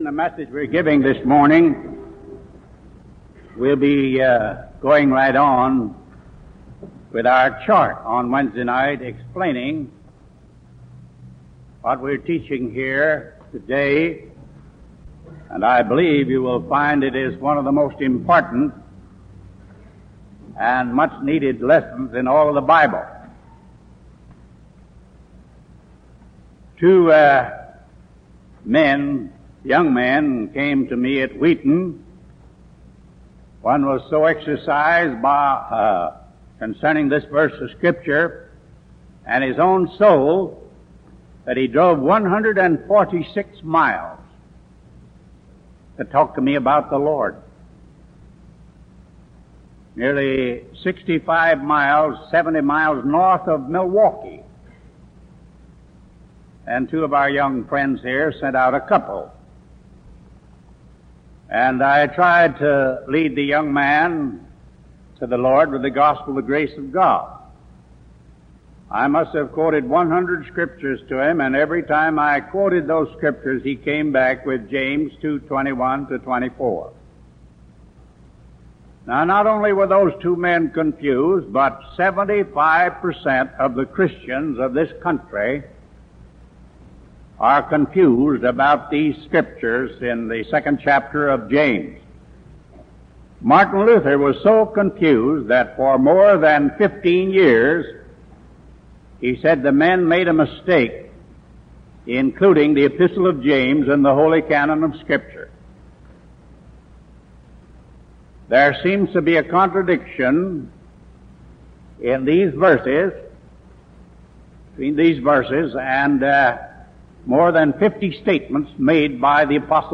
When you're done, explore more sermons .